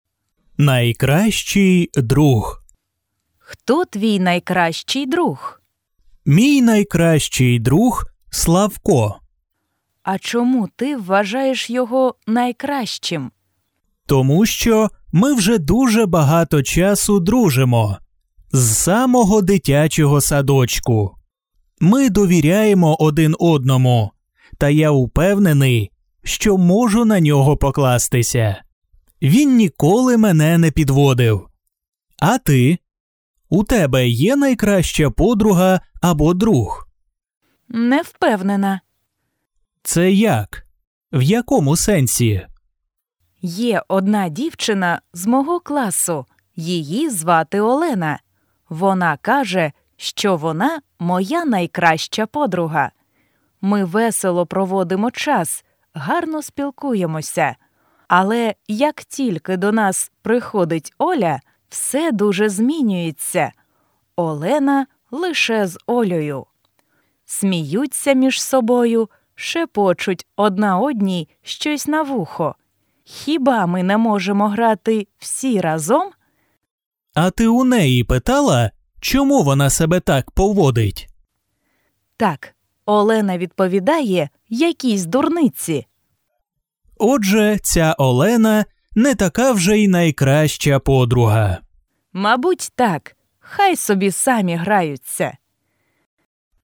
Dialogues